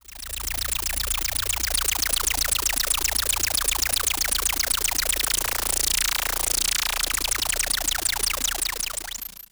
Roland E Noises
Roland E Noise 12.wav